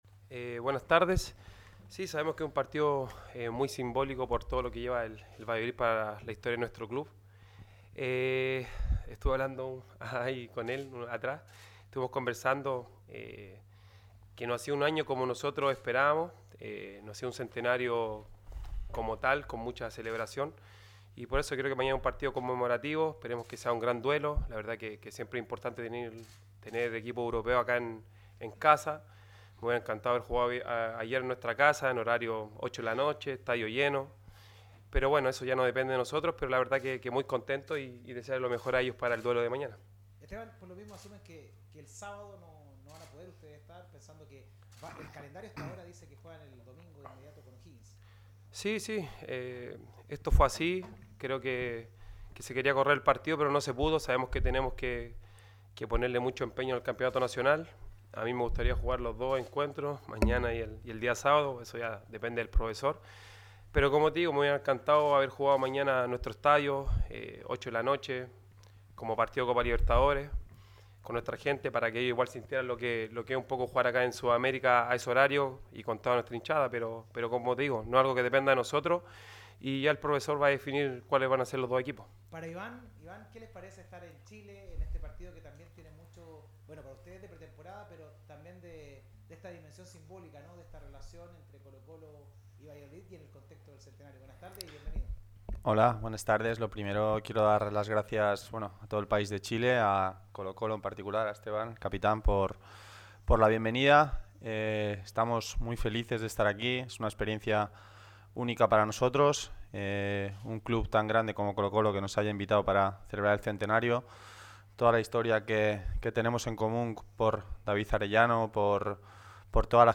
Tras la primera sesión del día, y como previa al duelo del miércoles (22.00 hora peninsular española) frente a Colo-Colo en el Estadio Sausalito de Viña del Mar, el blanquivioleta Iván Alejo y el colocolino Esteban Pavez atendieron a los medios de comunicación. Una comparecencia en la que cada uno resaltó la grandeza del otro Club, coincidiendo en que esta primera prueba tiene que ser un espectáculo.